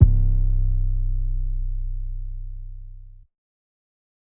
Major 808 1.wav